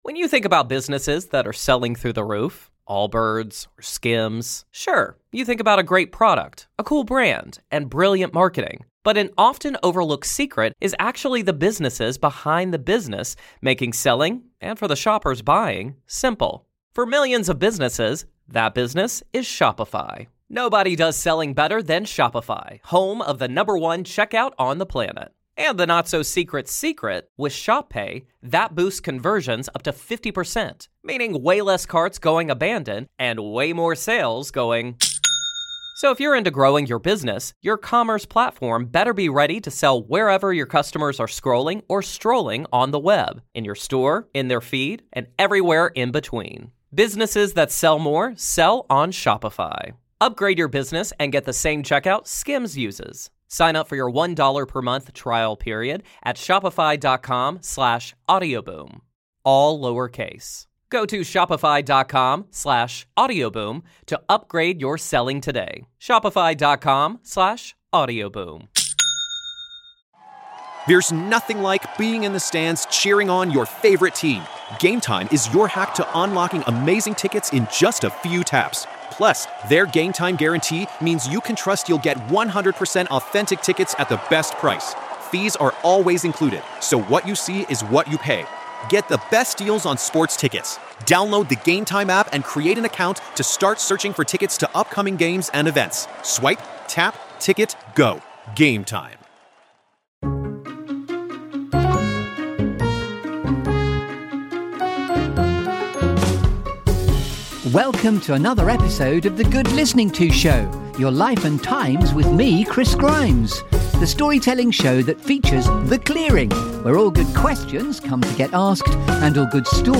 This feel-good Storytelling Show that brings you ‘The Clearing’.
Think Stories rather than Music!